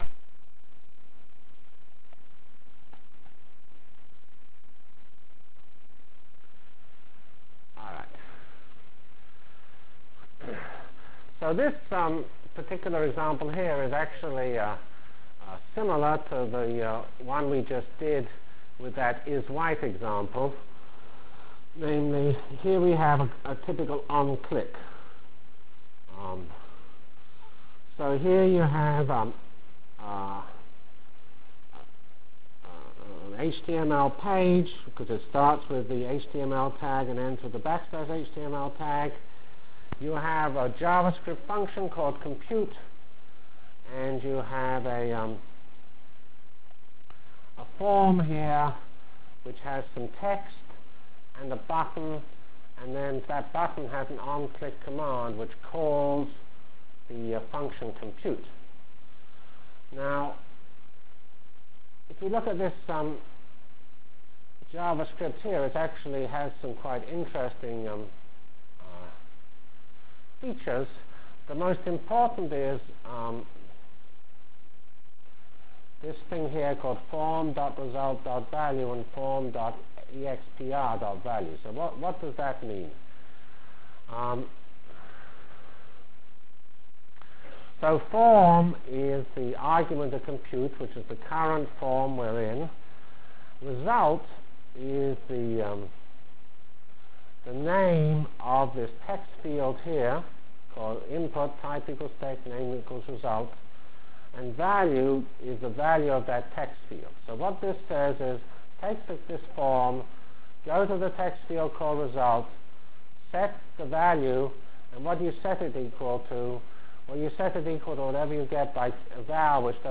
Full HTML for GLOBAL Feb 12 Delivered Lecture for Course CPS616 -- Basic JavaScript Functionalities and Examples